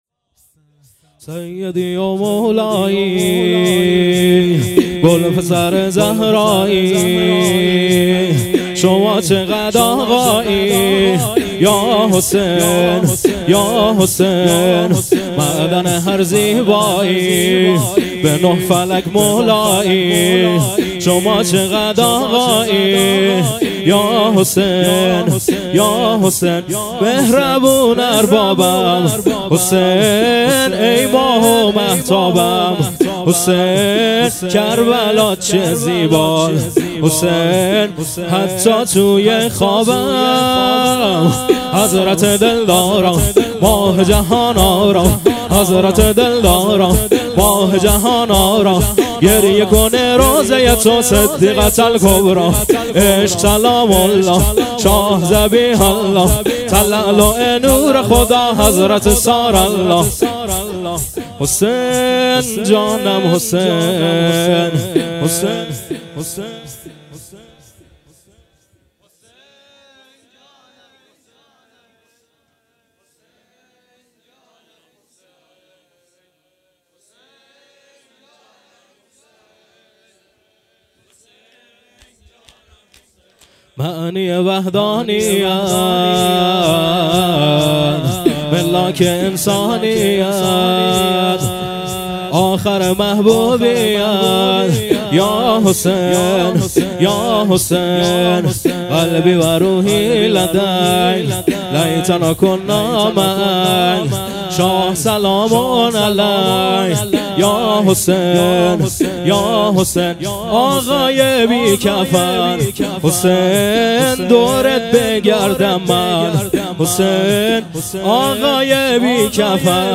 شور
مراســم عـزادارى شـب چهارم محرّم
محرم 1402 - شب چهارم